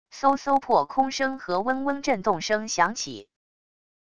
嗖嗖破空声和嗡嗡震动声响起wav音频